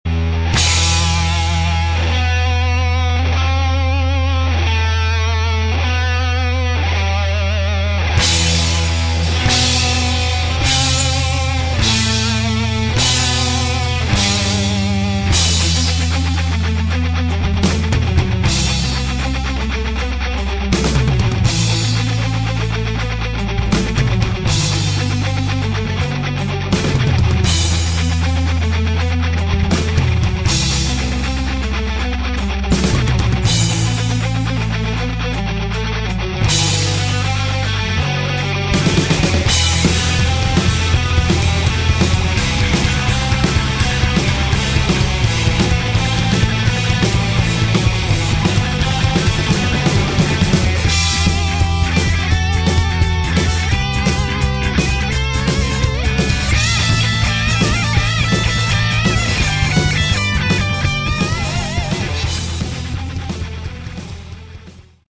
Category: Hard Rock
bass, lead vocals
guitars, vocals
percussion, vocals